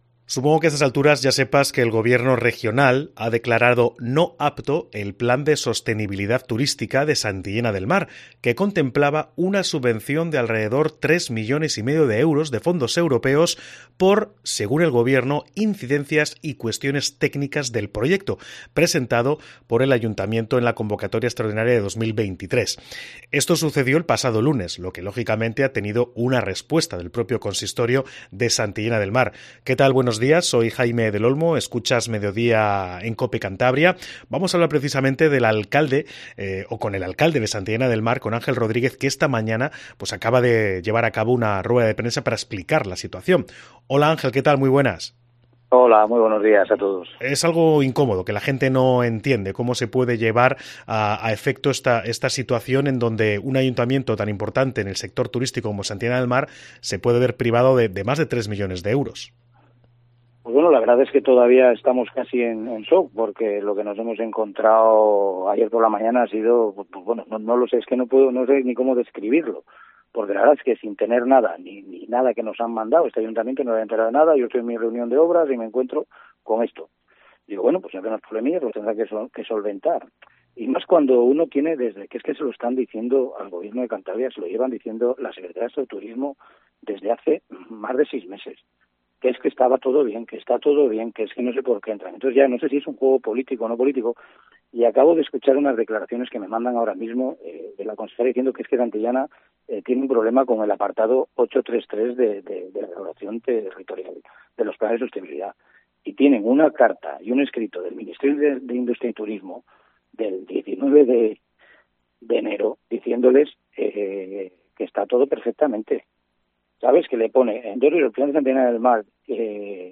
Entrevista alcalde Santillana del Mar